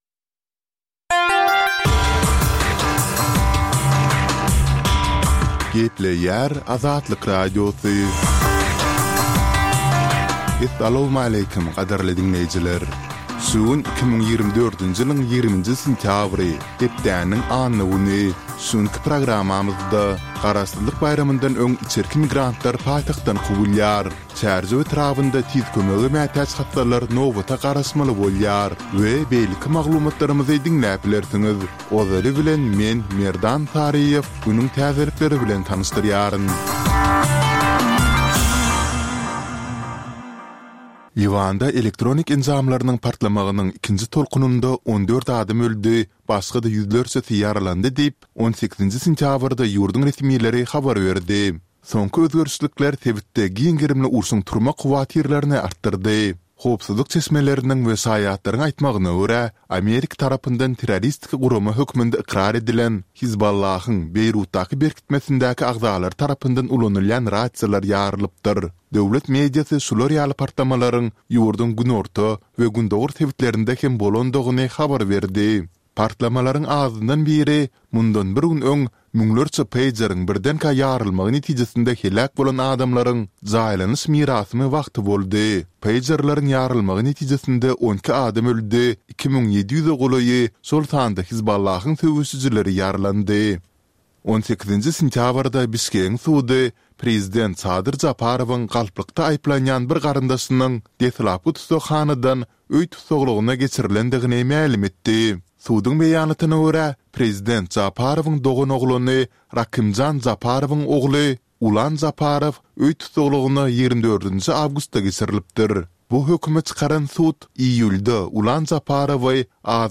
Täzelikler